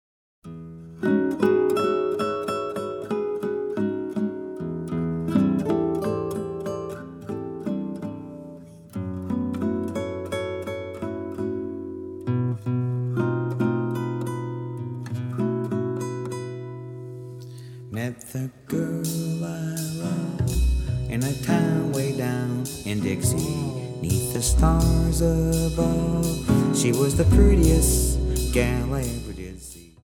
acoustic seven-string guitar
electric guitar, vocals
pedal steel guitar
acoustic bass
drums